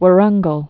(wə-rŭnggəl, wôrəng-)